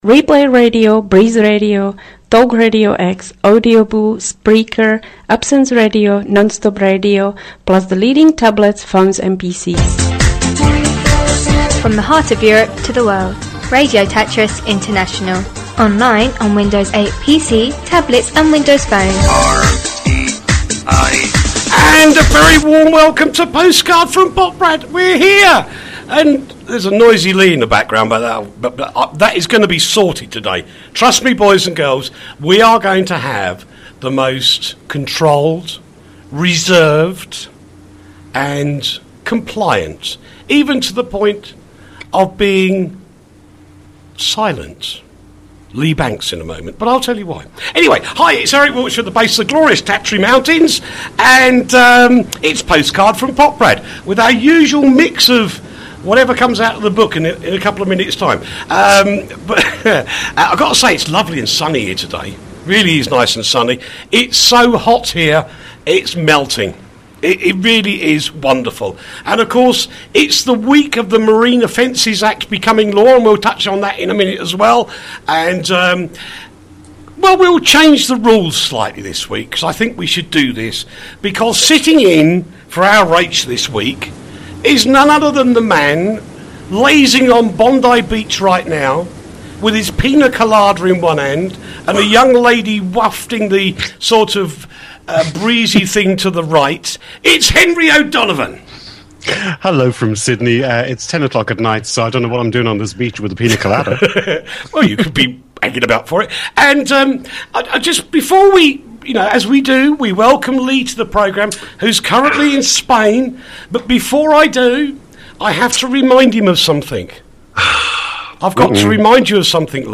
the alternative news show